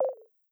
Minimize2.wav